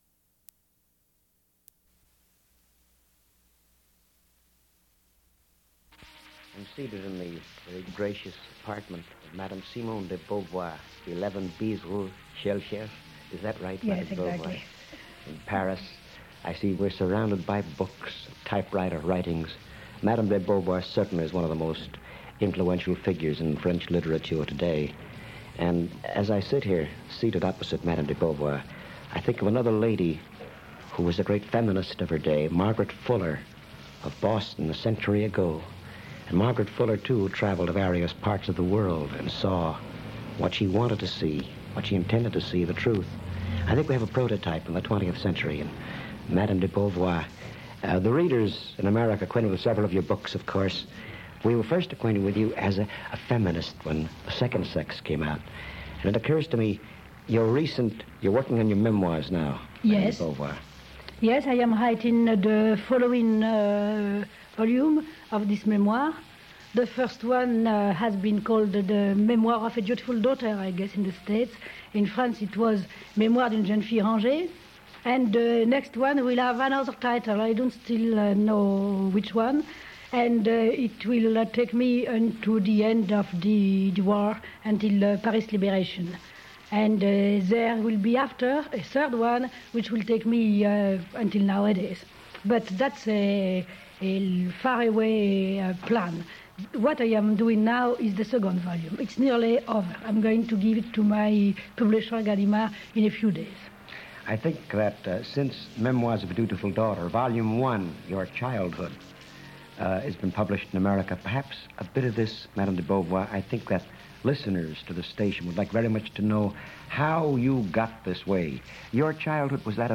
Download File Title Simone de Beauvoir discusses the art of writing Subject Lesbian radio Feminist literature Coming of age Women authors Description Recorded in Paris with Studs Terkel, Simone de Beauvoir describes her philosophies of life and writing her memoirs, discussing: her childhood upbringing in Catholic school, dynamics with her parents, artistic influences in her life, the experience of being a woman writer, and wartime.
Radio talk shows